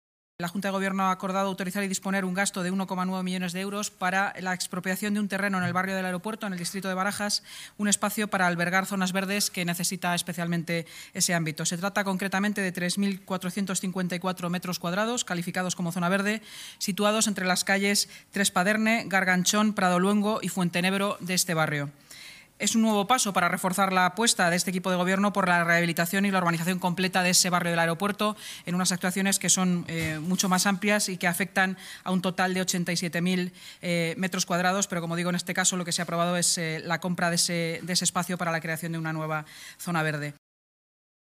Nueva ventana:La portavoz municipal, Inmaculada Sanz, detalla el acuerdo de la Junta de Gobierno para ampliar las zonas verdes del distrito de Barajas